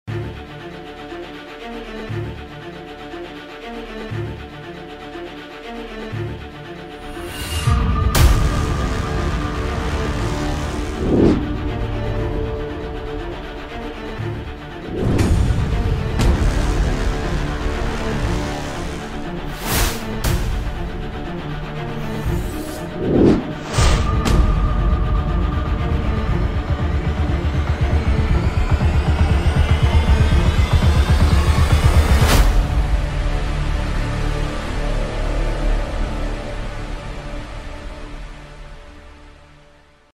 Тревожная мелодия для погони